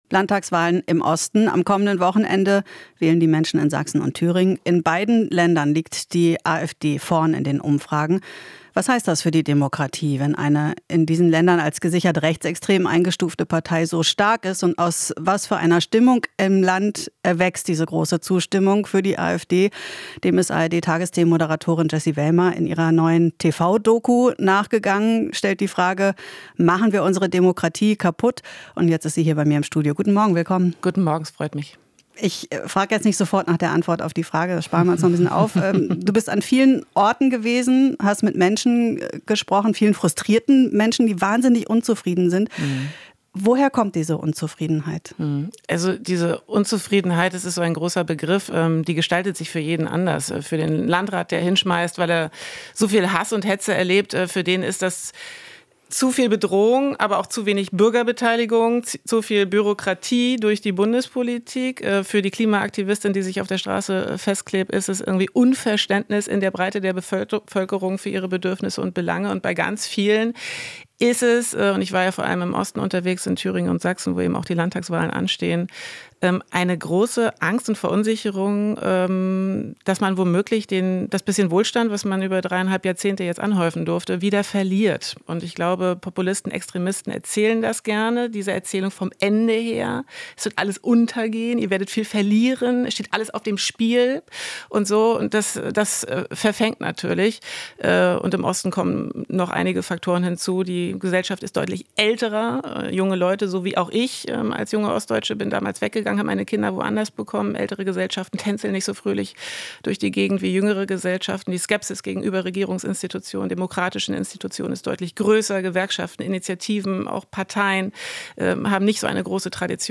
Interview - Jessy Wellmer unterwegs im Land: "Die Menschen wollen reden"